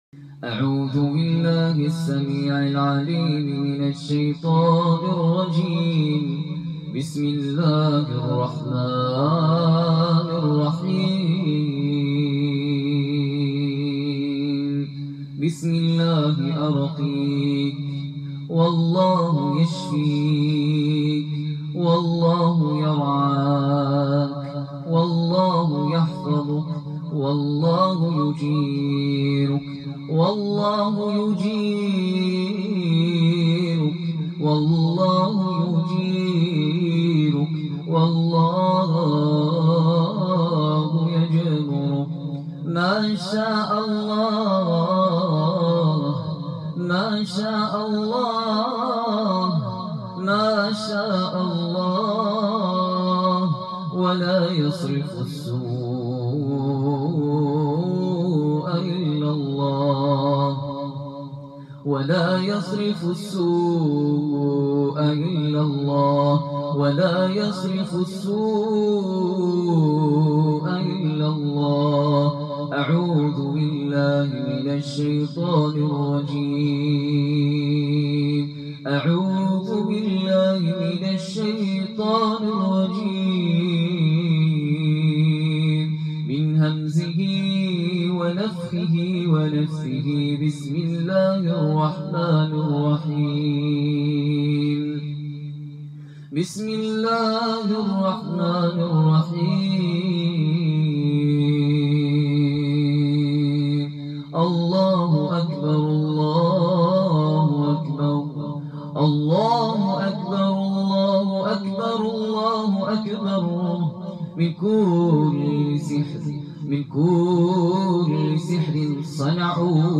বিবাহ বন্ধের যাদু নষ্টের রুকইয়াহ— Ruqyah For Remove Marriage Blockage